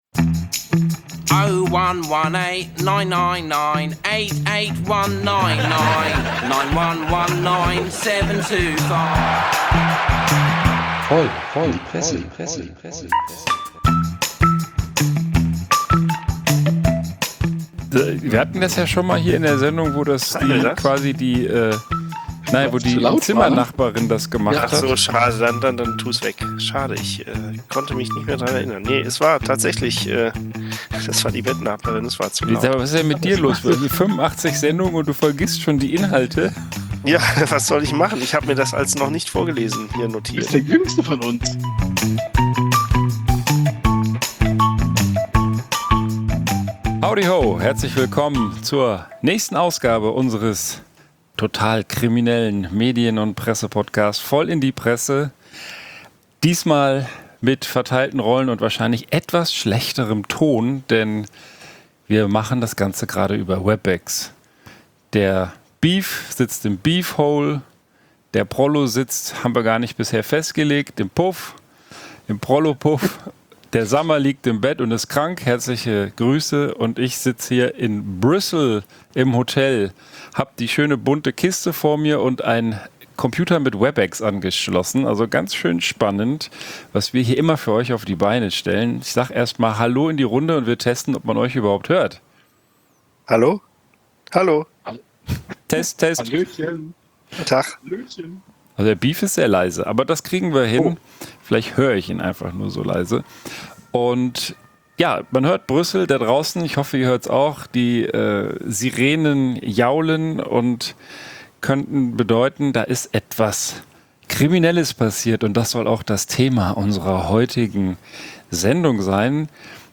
Dabei beleuchten wir uns den True-Crime-Trend, erfahren woher die 110 kommt und erzählen uns noch von der ein oder andere Räuberpistole. Diesmal virtuell zwischen Brüssel und Bonn, in kleiner Besetzung und tatsächlich – streckenweise – etwas ernsthafter als man es von unserem Herrenkränzchen gewohnt ist.